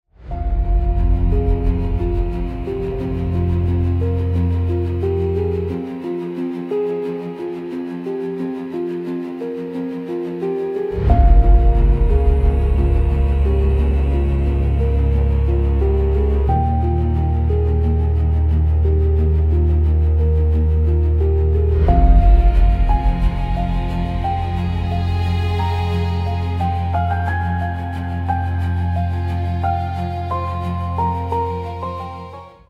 Dark Atmospheric
Dark textures and tension-driven soundscapes for suspense.